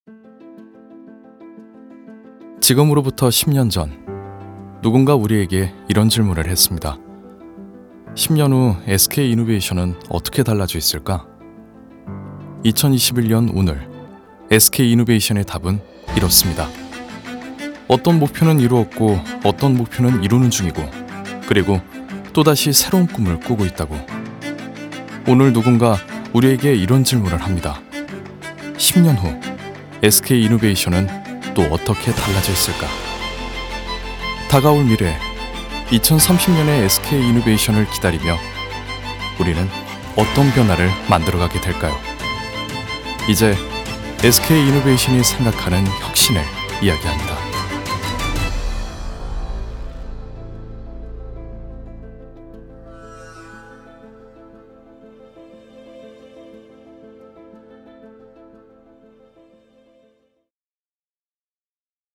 남자